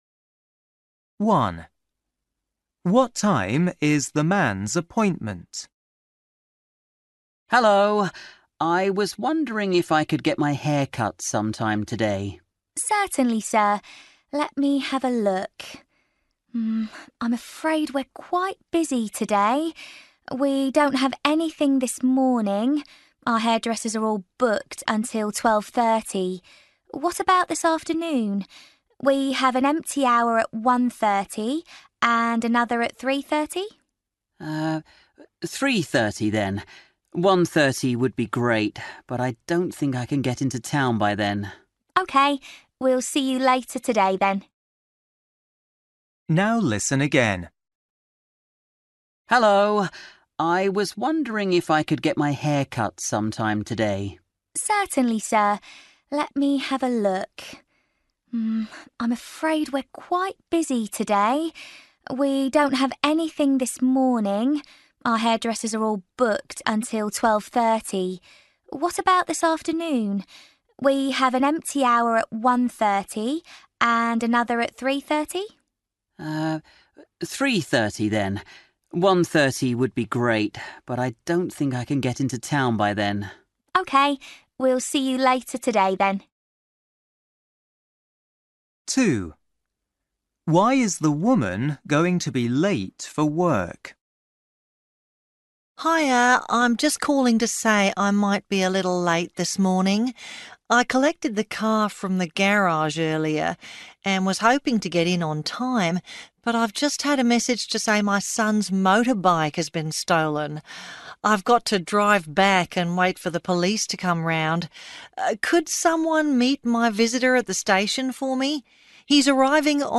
Listening: Everyday Conversations and Activities